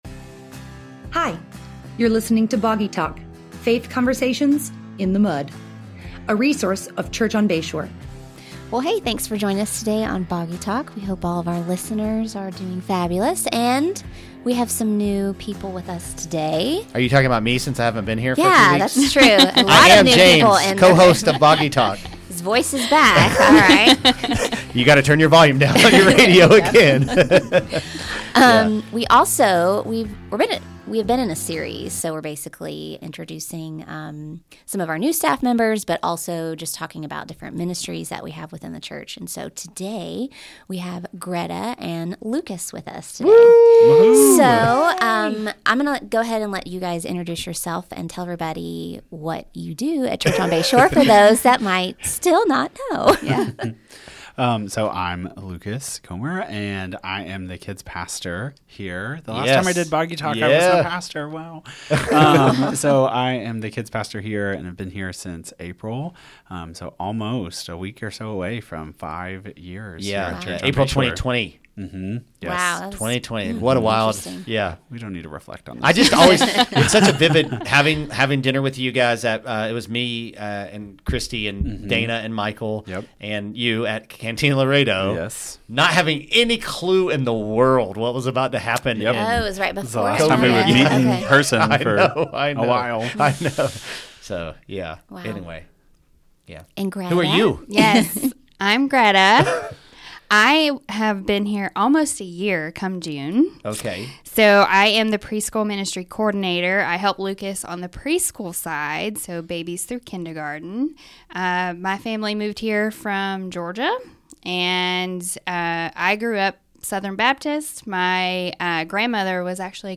We typically want every matter of faith and life to have crystal clear answers, but it isn’t always that easy. Recorded on the Boggy Bayou of Niceville, Florida